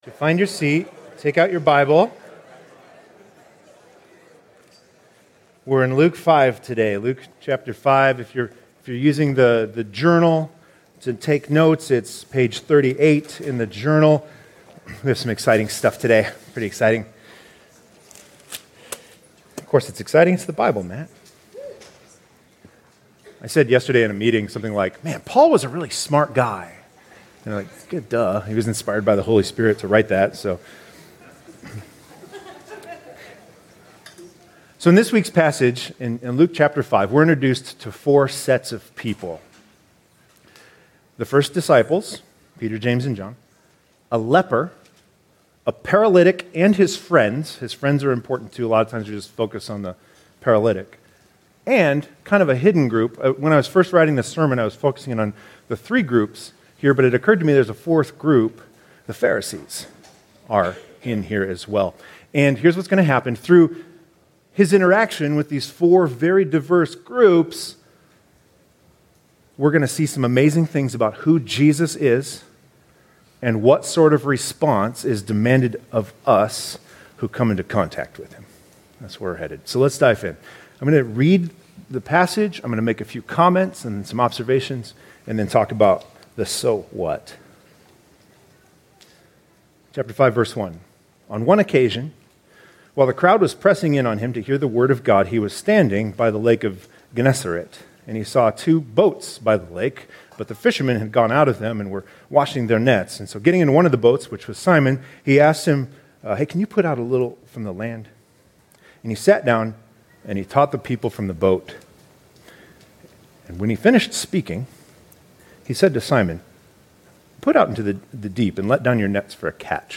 2021 Stay up to date with “ Stonebrook Church Sermons Podcast ”